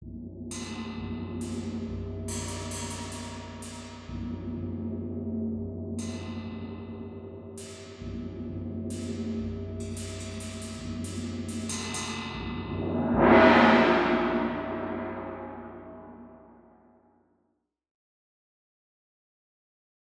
Gong
Instrument o nieokreślonej wysokości dźwięku; zapis tylko rytmu.
Gong wprowadza się w drgania za pomocą uderzenia pałką lub młoteczkiem. Rodzaj dźwięku zależy od miejsca uderzenia.
Dźwięki instrumentów są brzmieniem orientacyjnym, wygenerowanym w programach:
Gong.mp3